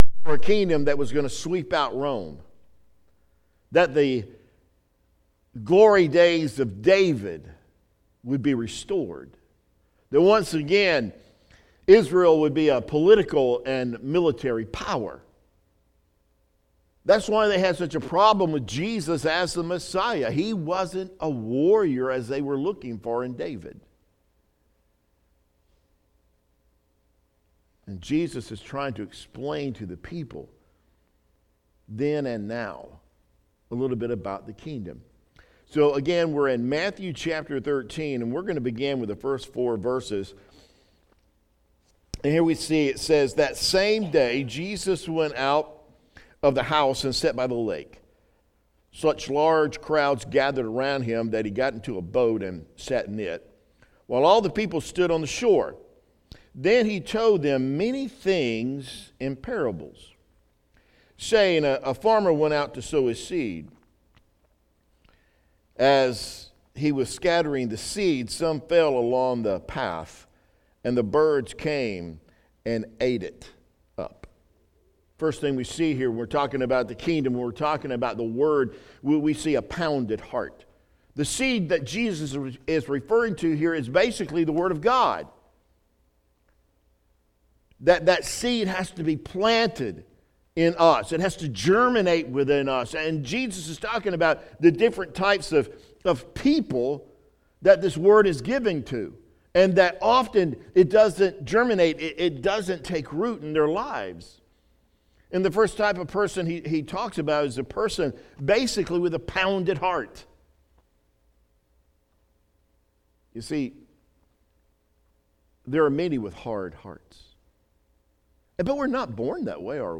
Sunday Morning Messgae